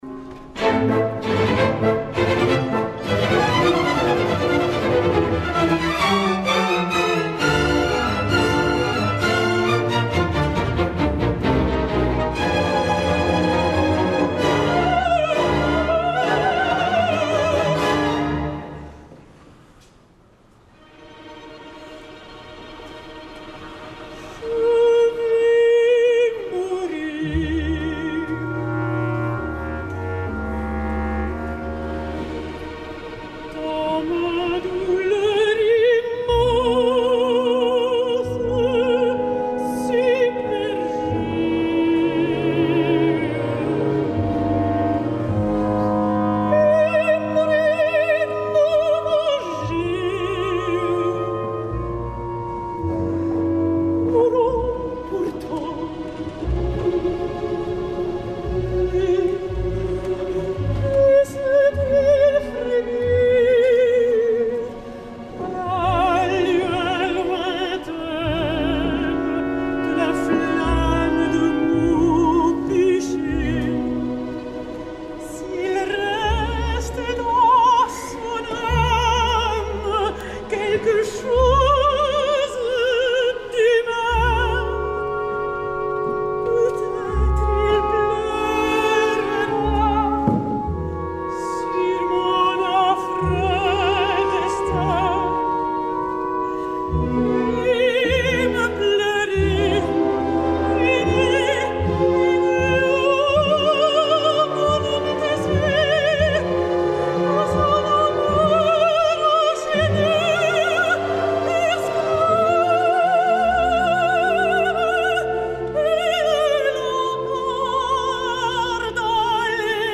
La tercera Didon és la malaguanyada mezzo Lorraine Hunt, especialista en el repertori barroc i clàssic, que va sorprendre a tothom interpretant meravellosament la reina de Cartago al MET, en l’edició del 2003, sota la direcció de James Levine.
Lorraine Hunt (Didon) i Elena Zaremba (Anna) al MET 2003
La seva ductilitat i sensibilitat vocal se’ns mostra en tota la seva puixança, en aquesta escena final i com les seves col·legues precedents, la interpretació de la Hunt és intensa, dolça i molt emotiva.
final-didon-lorraine-hunt.mp3